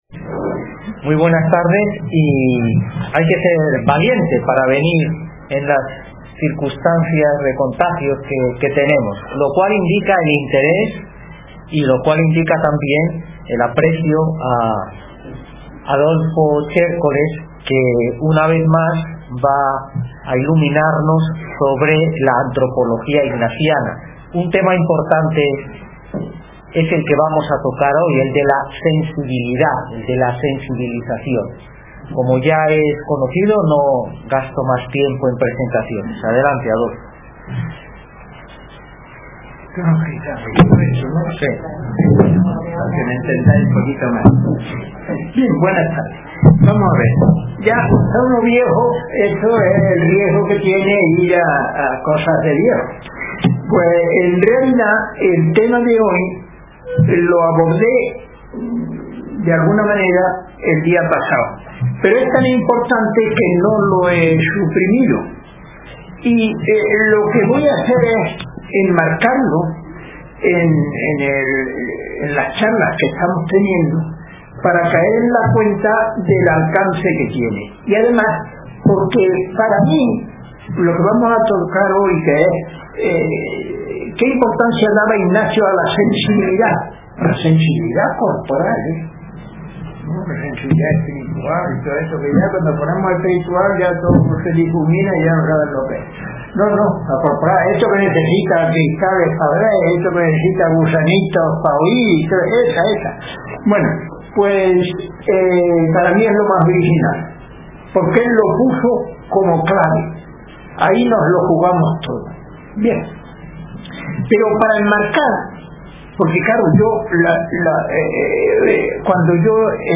conferencias-formación 21-22 en Antropología Ignaciana – Centro Arrupe Sevilla